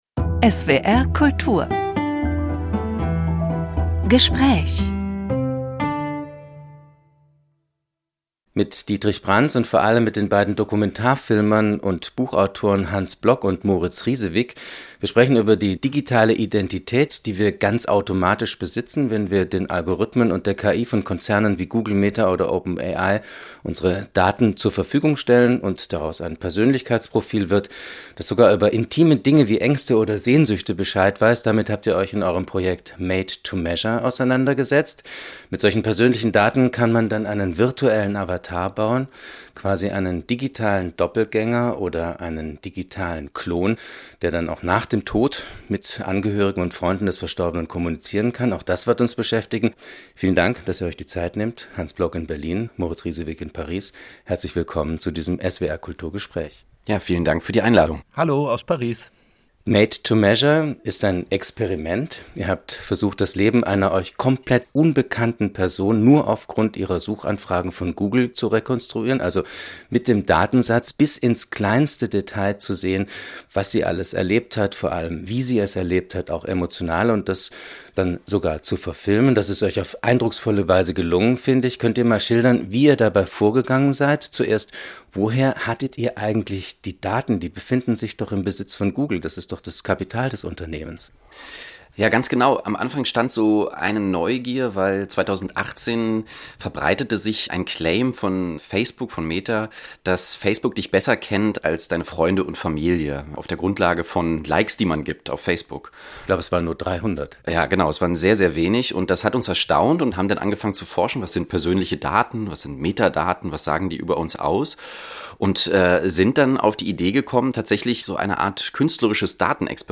Gespräch | KI und Kreativität (6/6)